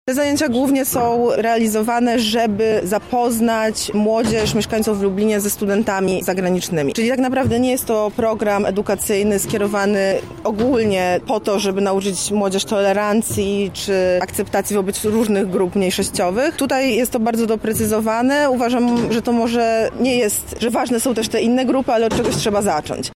Na konferencji poruszono zjawisko przemocy motywowanej ksenofobią.